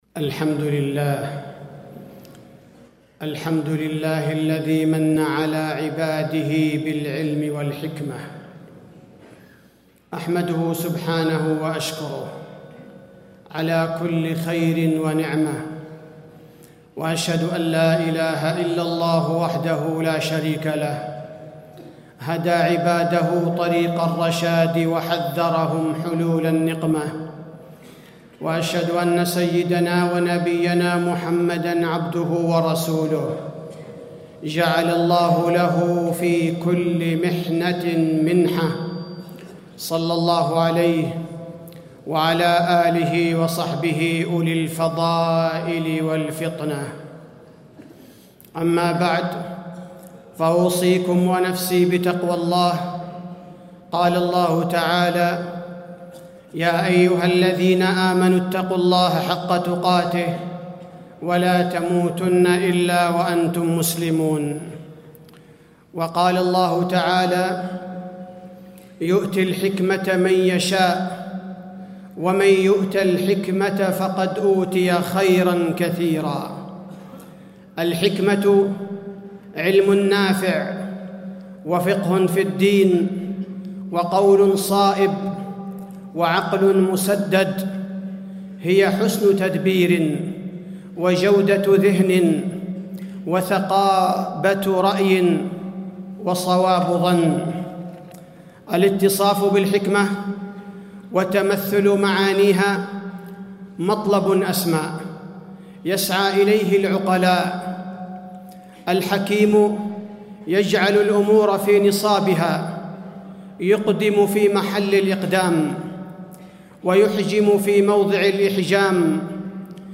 تاريخ النشر ١٤ ربيع الثاني ١٤٣٥ هـ المكان: المسجد النبوي الشيخ: فضيلة الشيخ عبدالباري الثبيتي فضيلة الشيخ عبدالباري الثبيتي الحكمة معناها وفضلها